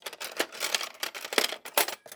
SFX_Cooking_Cutlery_01.wav